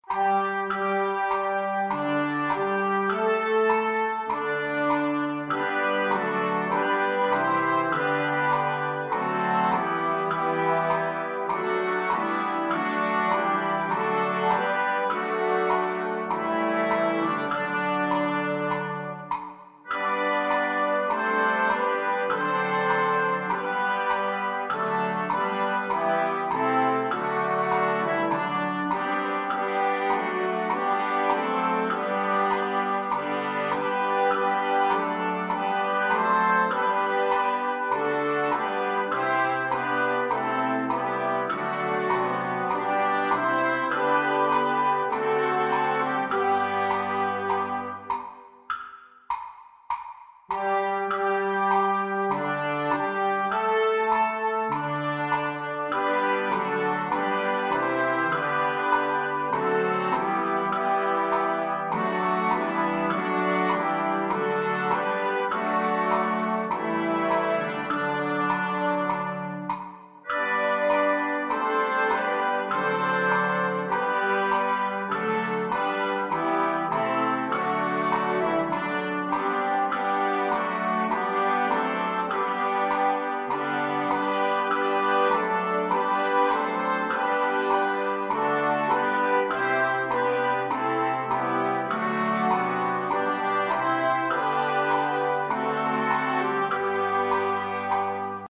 SOUND RECORDINGS/PRACTICE TRACKS:
Adeste Fidelis w Click Track.mp3